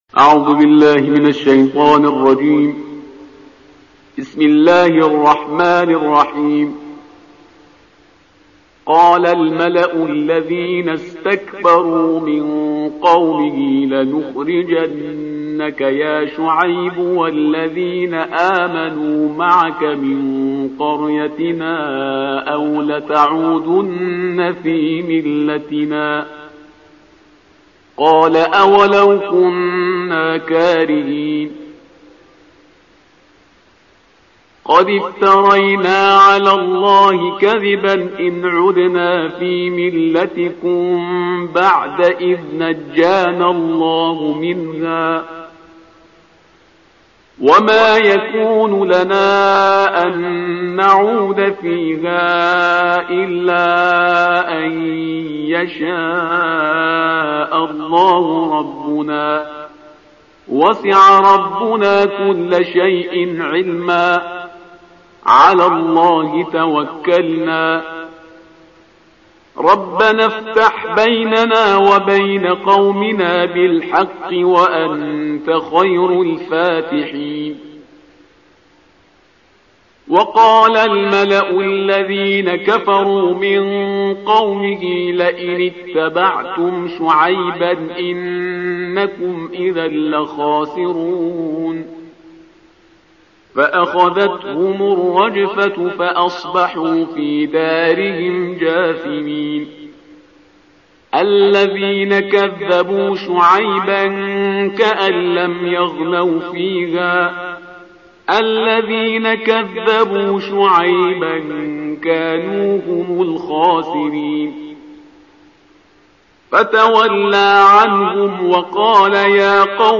تلاوت ترتیل جزء نهم کلام وحی با صدای استاد